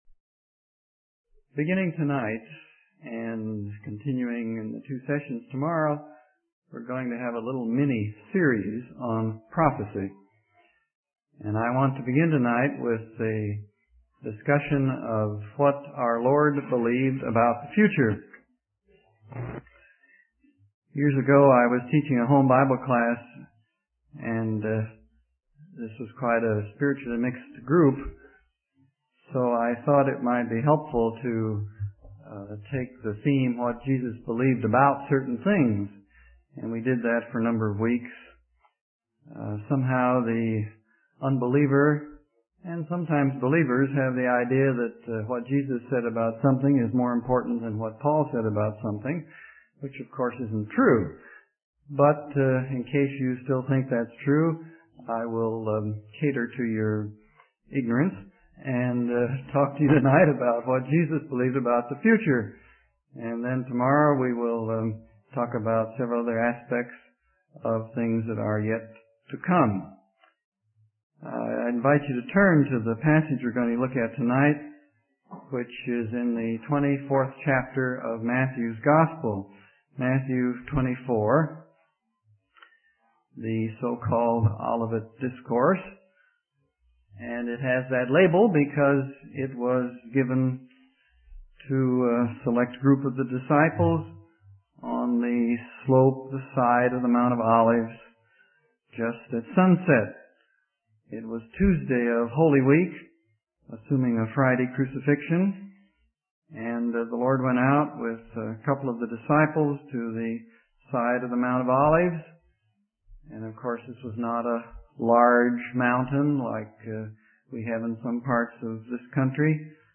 In this sermon, the preacher focuses on Jesus' conversation with his disciples about the signs of his coming and the end of the age. He emphasizes the importance of being alert and ready for the future, as believers do not know the exact timing of these events. The preacher uses the analogy of a sports game without a visible clock to illustrate the unpredictability of the future.